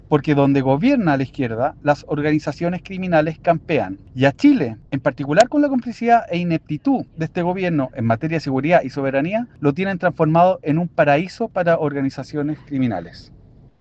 En la misma línea, el jefe de bancada del Partido Republicano, Juan Irarrázaval, criticó duramente al Ejecutivo, asegurando que la debilidad del Ejecutivo en materia de seguridad ha permitido el ingreso de este tipo de organizaciones criminales.